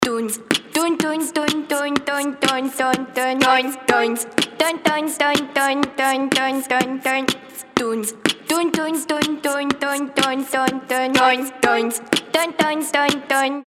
• Качество: 320, Stereo
ритмичные
веселые
голосовые
битбокс